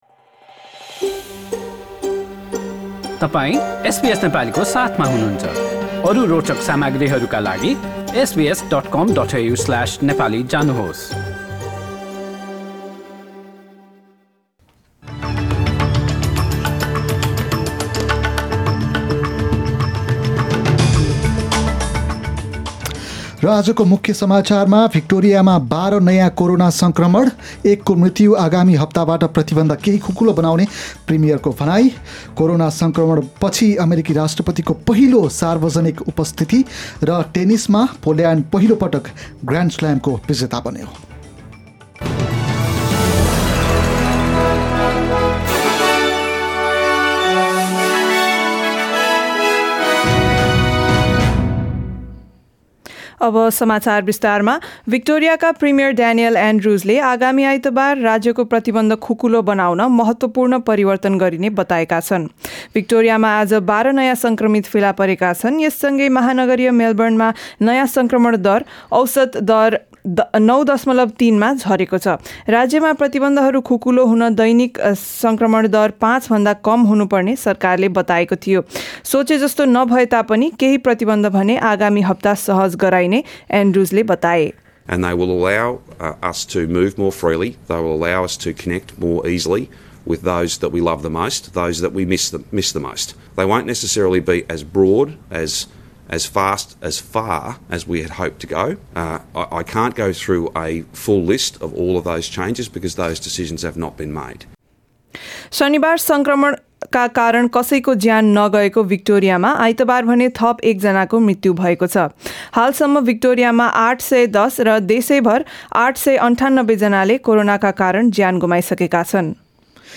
Listen to the latest news headlines in Australia from SBS Nepali radio.